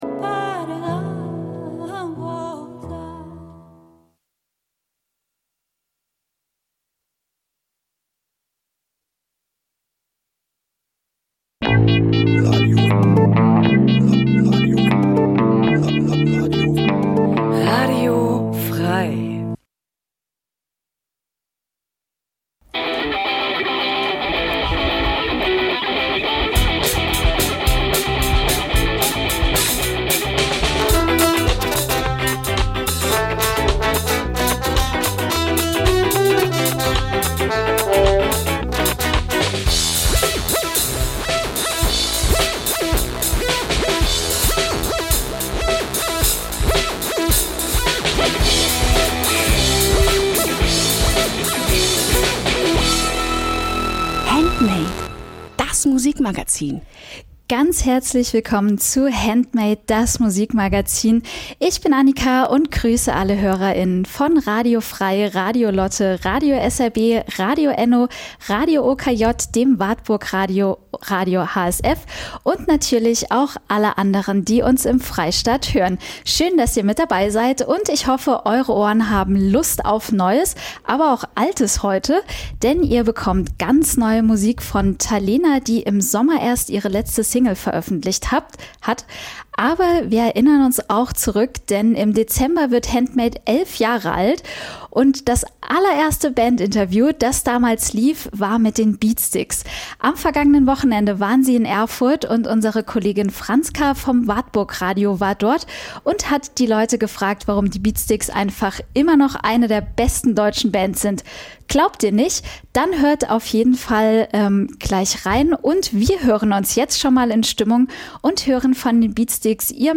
Jeden Donnerstag stellen wir euch regionale Musik vor und scheren uns dabei nicht um Genregrenzen.
Wir laden Bands live ins Studio von Radio F.R.E.I. ein, treffen sie bei Homesessions oder auf Festivals.